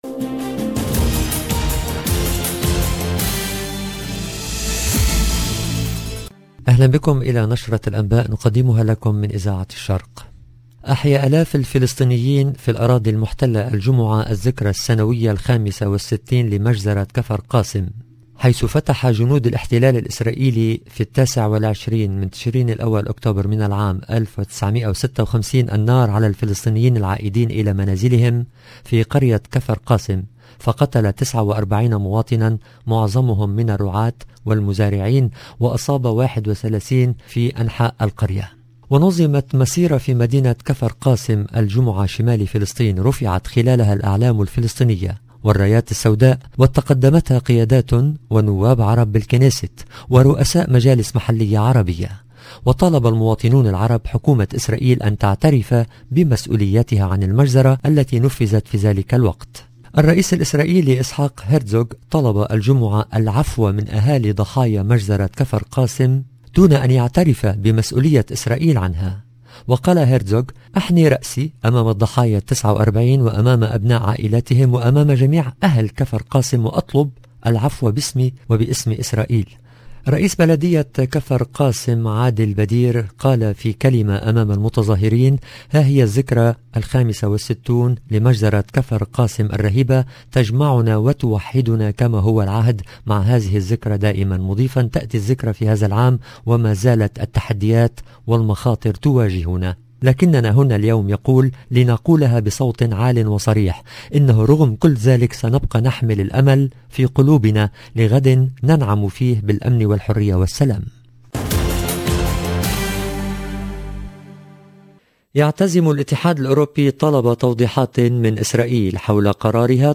LE JOURNAL DU SOIR EN LANGUE ARABE DU 29/10/21